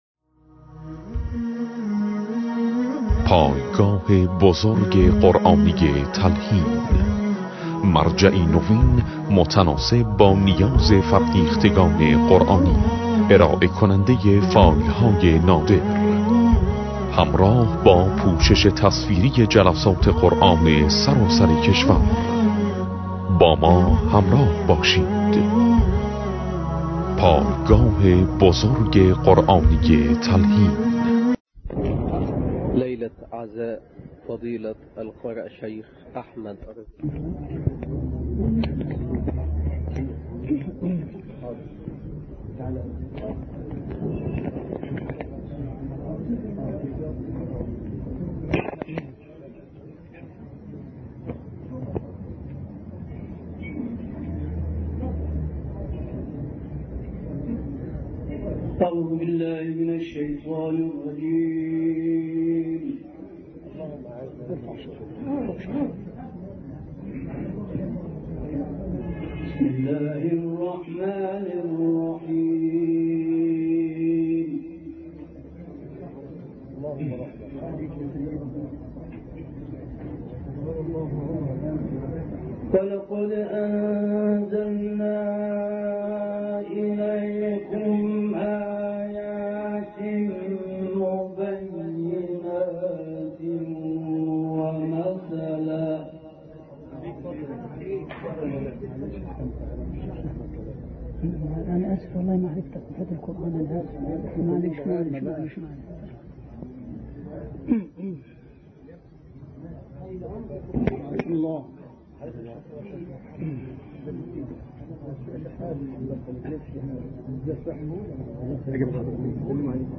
گروه فعالیت‌های قرآنی: تلاوت سوره نور با صدای شیخ محمد احمد شبیب، در مجلس ترحیم شیخ احمد الرزیقی اجرا شده است، می‌شنوید.
به گزارش خبرگزاری بین المللی قرآن (ایکنا) تلاوت آیات 34 تا 38 سوره نور توسط شیخ احمد شبیب، قاری برجسته مصری در مجلس ختم شیخ احمد الرزیقی در مسجد عمر مکرم قاهره در کانال تلگرامی پایگاه قرآنی تلحین منتشر شده است.